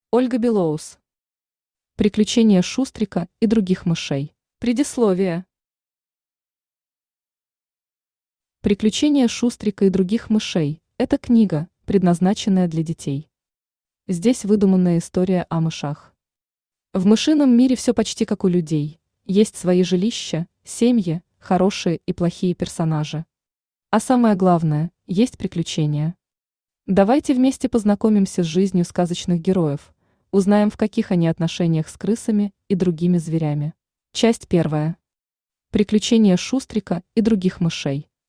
Aудиокнига Приключения Шустрика и других мышей Автор Ольга Билоус Читает аудиокнигу Авточтец ЛитРес.